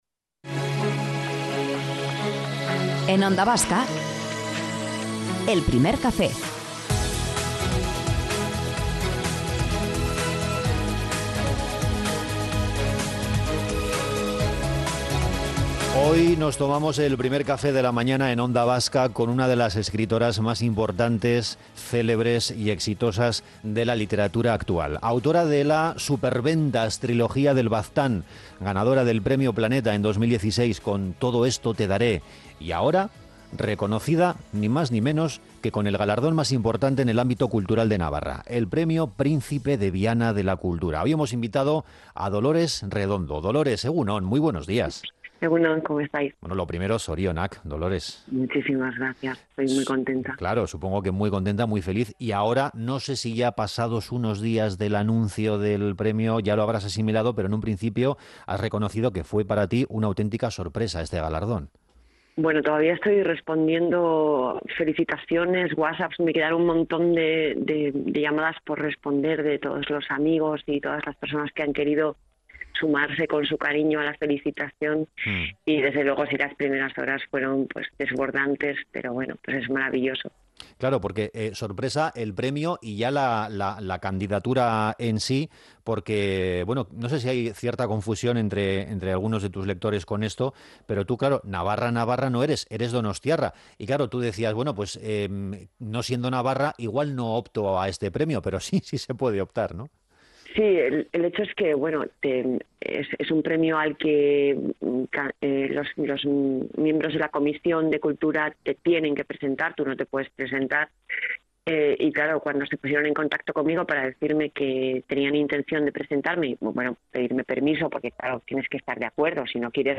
En Onda Vasca hemos ahondado en esta relación tan especial en una entrevista amable y que demuestra que ser una superventas y autora de best seller tras best seller no la ha cambiado.